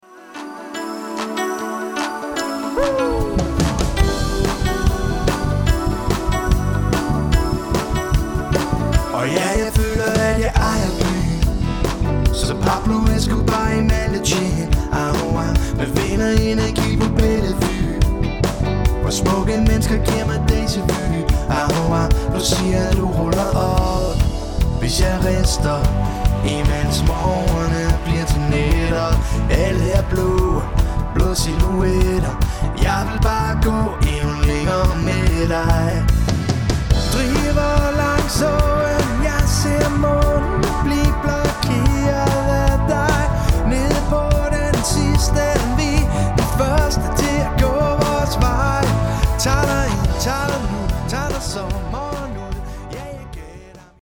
Professionel - Allround party band
• Coverband